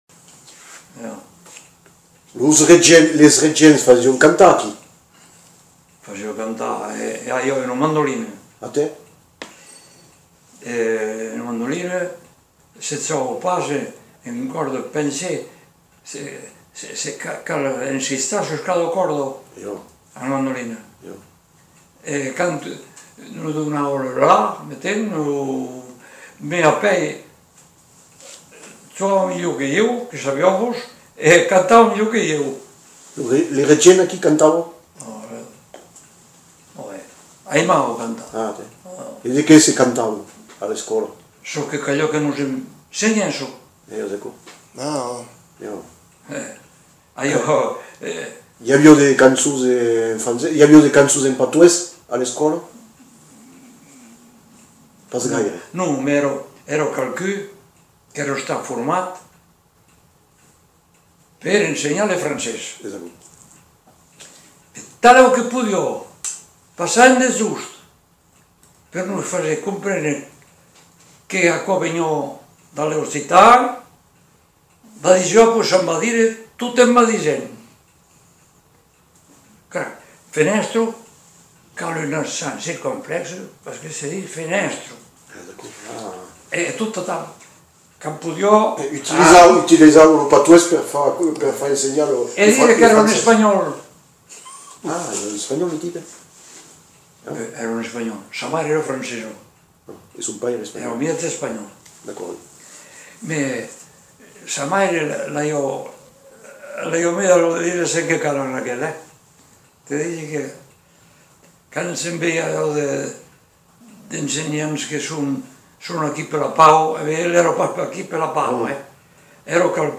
Aire culturelle : Lauragais
Lieu : Le Faget
Genre : témoignage thématique